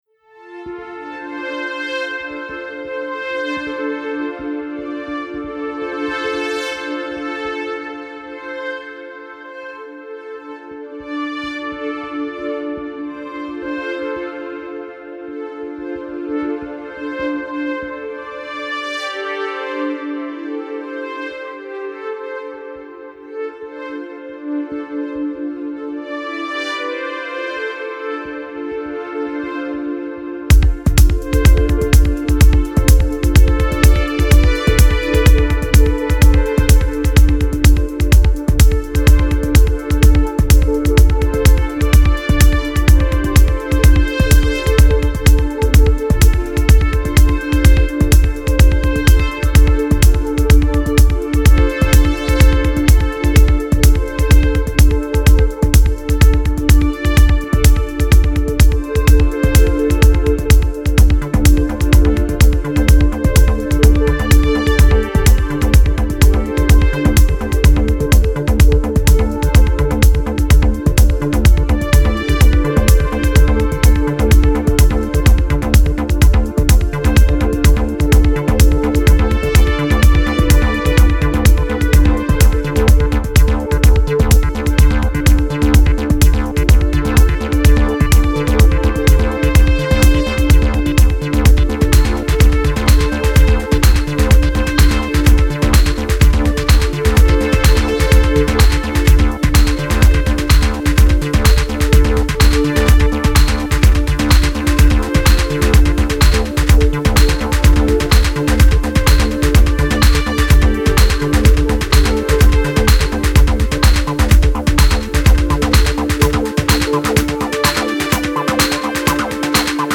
More energetic I feel.